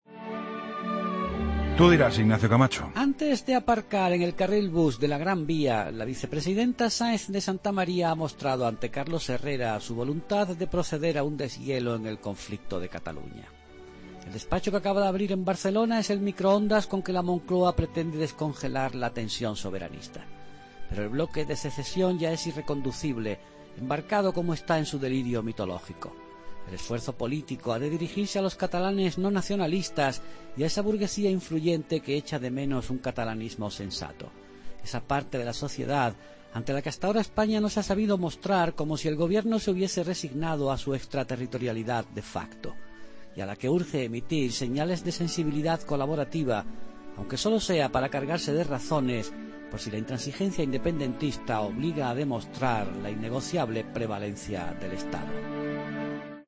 AUDIO: El comentario de Ignacio Camacho en 'La Linterna'